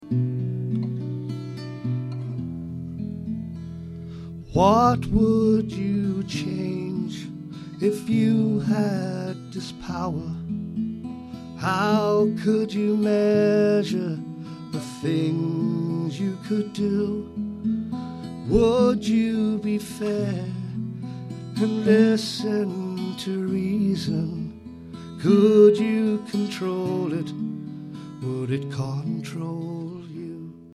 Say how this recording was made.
Ashington Folk Club - 01 February 2007